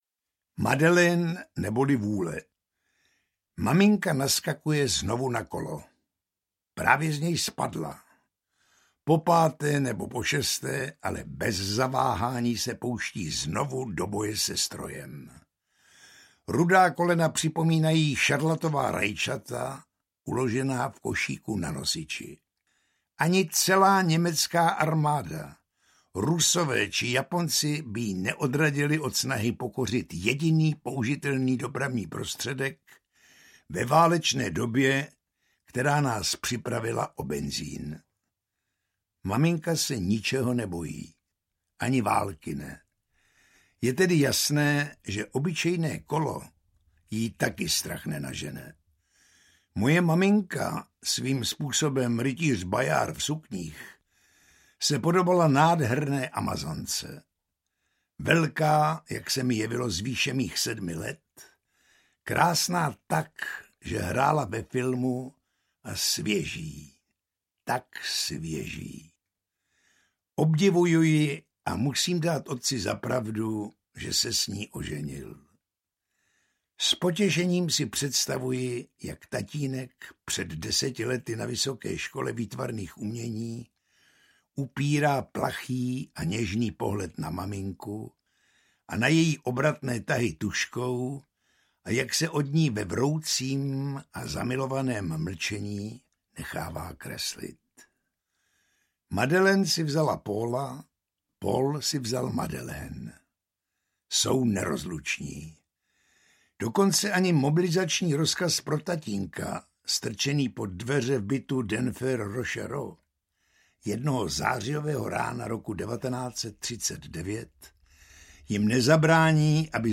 Belmondo: Mých tisíc životů audiokniha
Ukázka z knihy